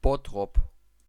Bottrop (German pronunciation: [ˈbɔtʁɔp]
De-Bottrop.ogg.mp3